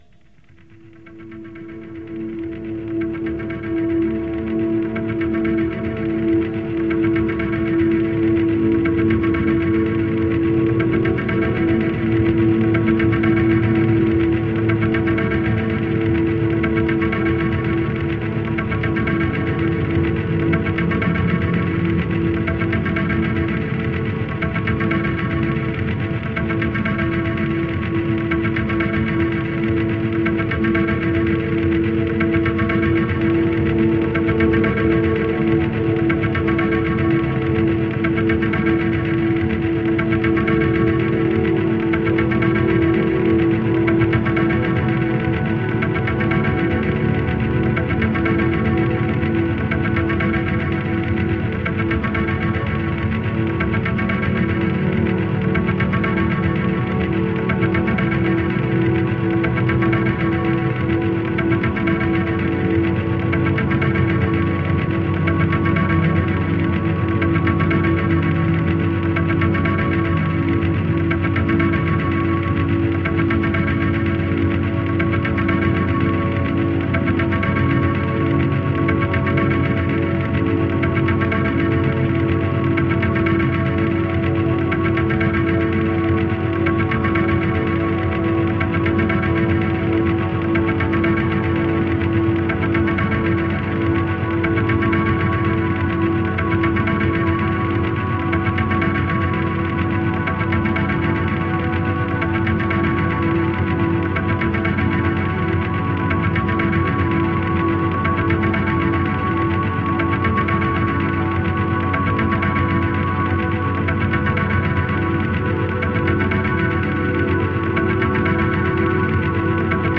Ambient/world dream field.
Tagged as: Ambient, New Age, Industrial, Remix, Space Music